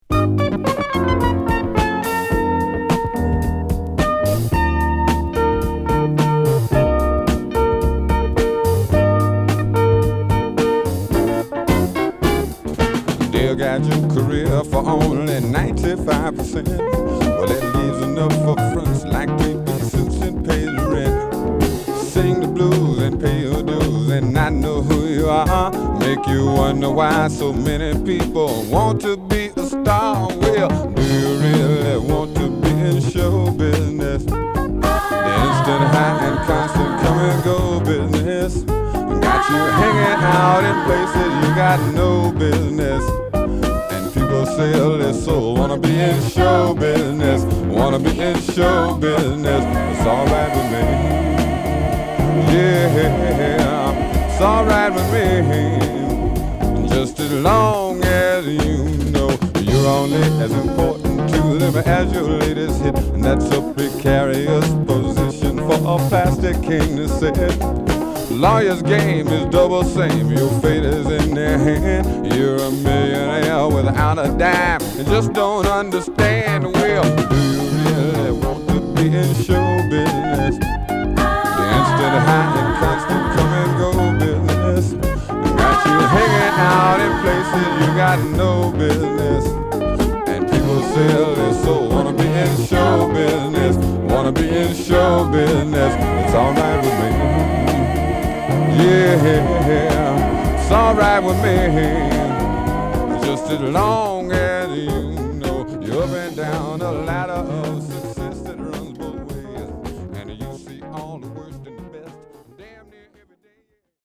コミカルなイントロから始まる彼らしいビターソウルを披露！
(Stereo)